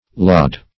lodde - definition of lodde - synonyms, pronunciation, spelling from Free Dictionary Search Result for " lodde" : The Collaborative International Dictionary of English v.0.48: Lodde \Lod"de\ (l[o^]d'd[-e]), n. (Zool.)